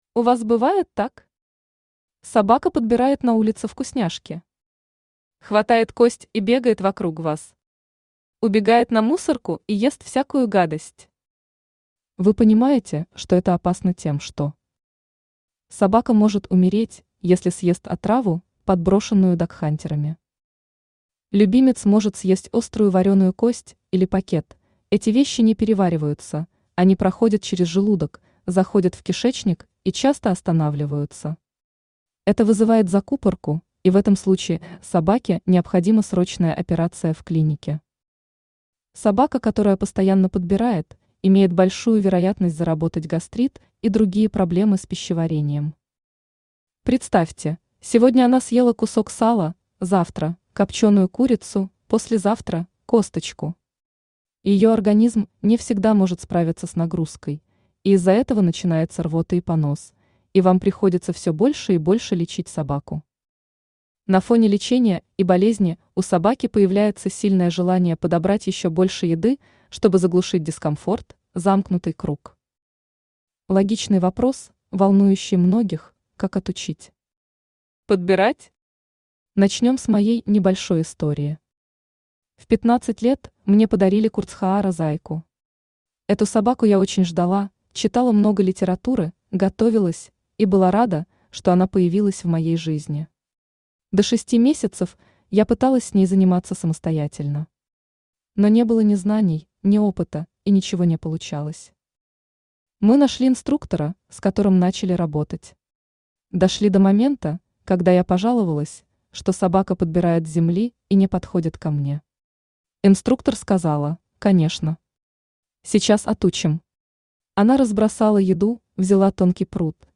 Как отучить собаку подбирать с земли Автор Ирина Олеговна Безуглая Читает аудиокнигу Авточтец ЛитРес.